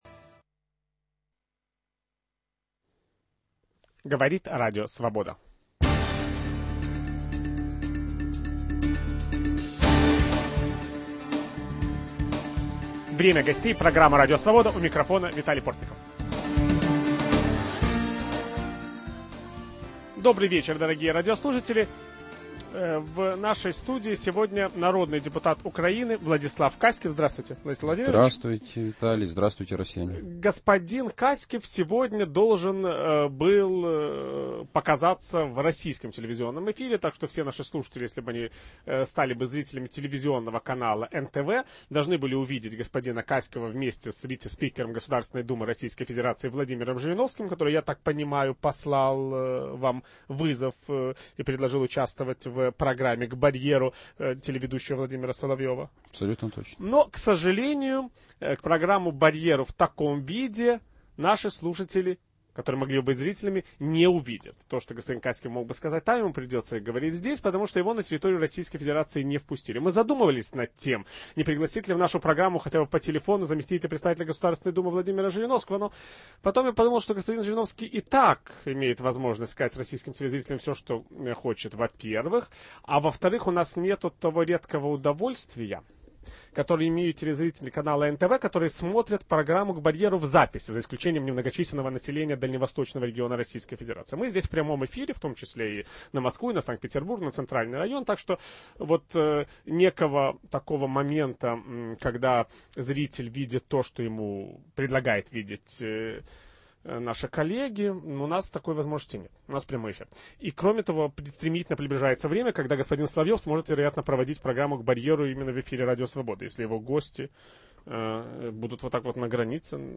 В нашей студии – народный депутат Украины Владислав Каськив.